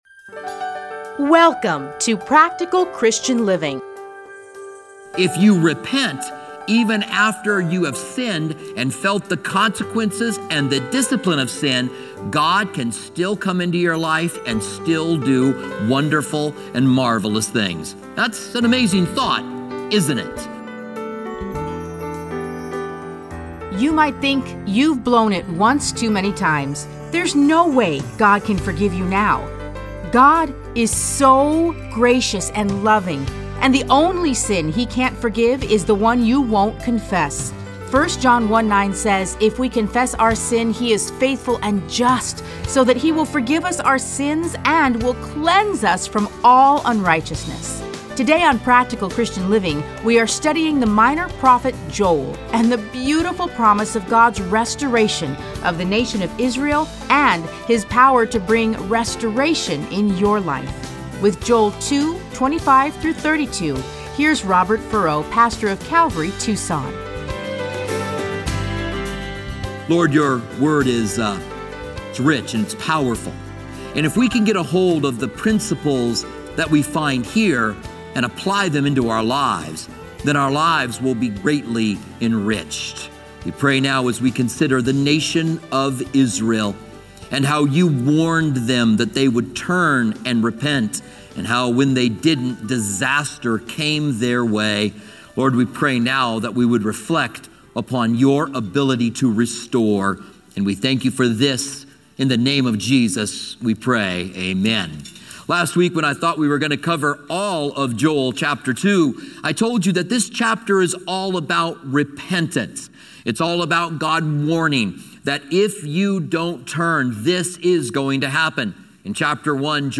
Listen to a teaching from Joel 2:25-32.